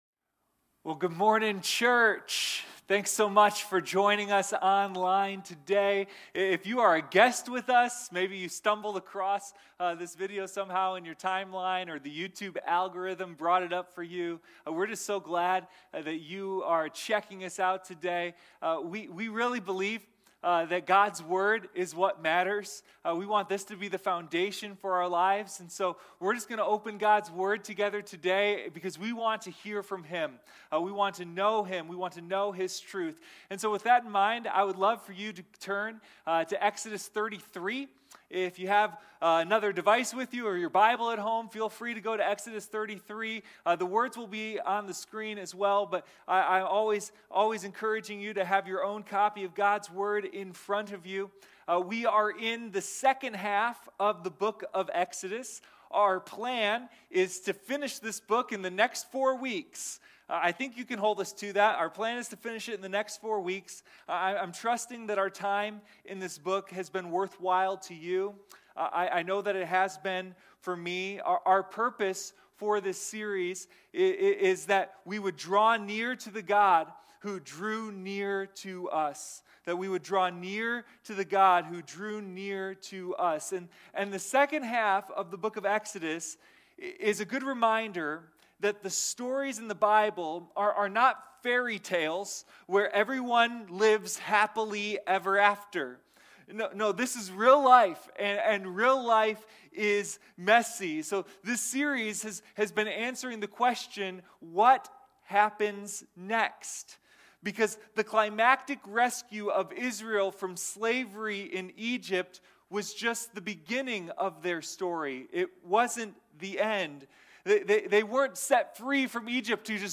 Sunday Morning Communion